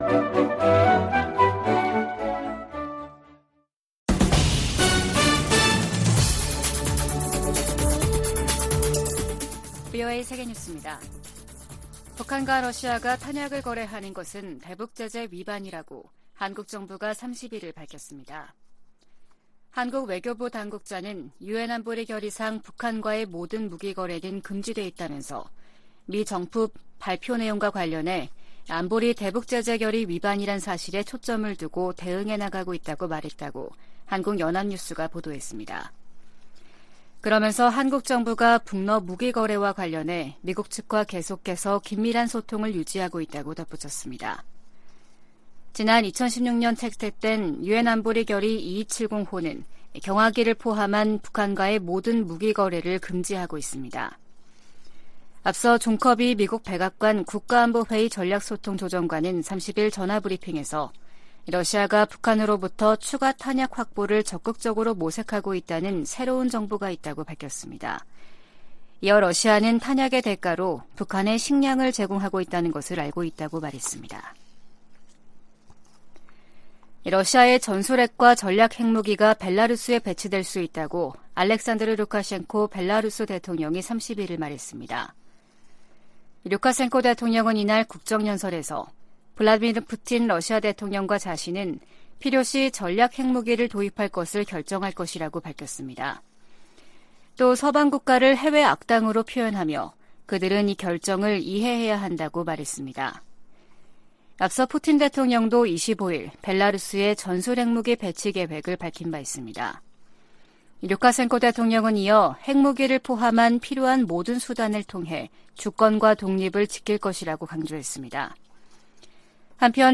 VOA 한국어 아침 뉴스 프로그램 '워싱턴 뉴스 광장' 2023년 4월 1일 방송입니다. 미 재무부가 북한과 러시아의 무기 거래에 관여한 슬로바키아인을 제재 명단에 올렸습니다. 백악관은 러시아가 우크라이나 전쟁에서 사용할 무기를 획득하기 위해 북한과 다시 접촉하고 있다는 새로운 정보가 있다고 밝혔습니다. 미 국방부는 핵무기 한국 재배치 주장에 한반도 비핵화 정책을 계속 유지할 것이라고 밝혔습니다.